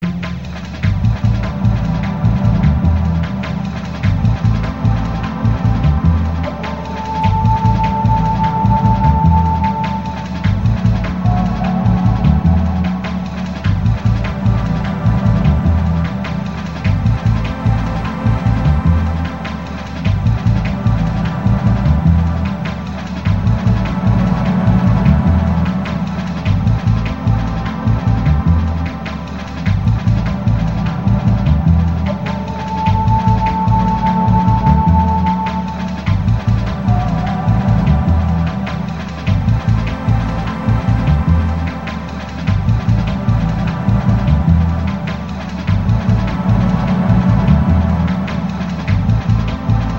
D&B第二弾。
上の曲に比べるとテンポは遅い。D&Bといってもチョット古臭い感じになってますね。